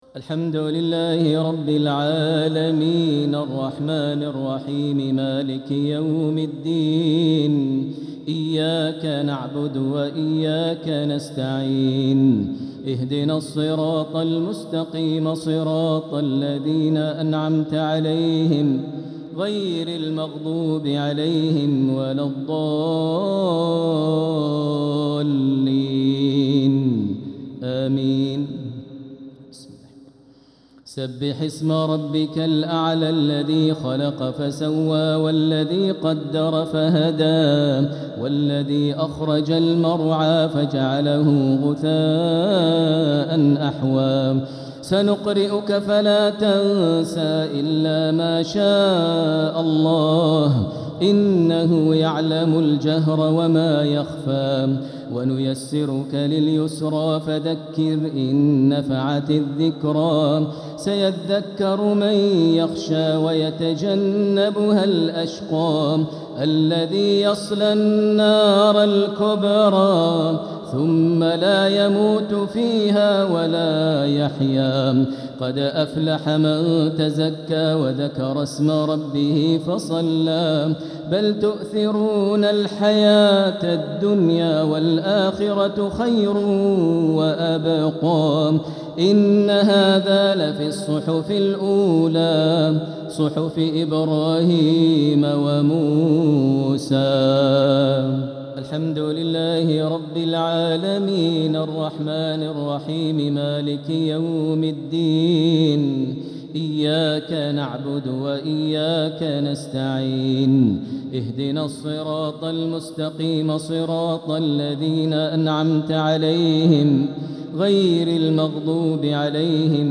صلاة الشفع و الوتر ليلة 8 رمضان 1446هـ | Witr 8th night Ramadan 1446H > تراويح الحرم المكي عام 1446 🕋 > التراويح - تلاوات الحرمين
Al-MuaiqlyWitr.mp3